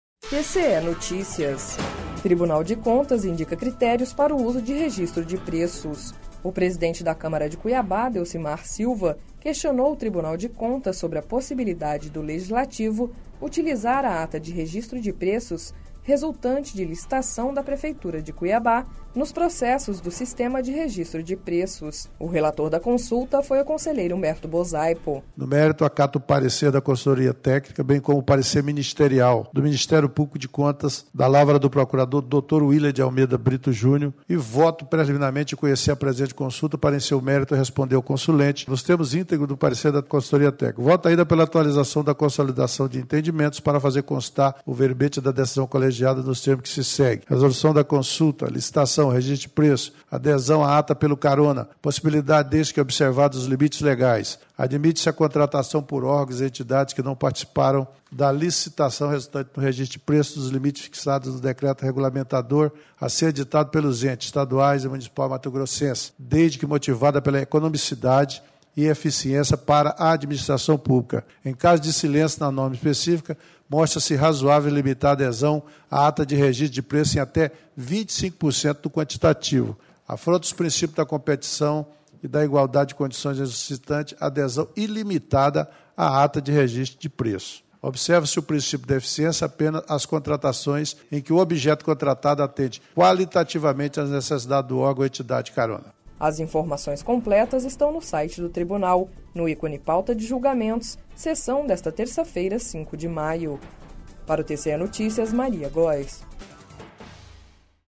Sonora: Humberto Bosaipo - conselheiro do TCE-MT